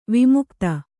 ♪ vimukta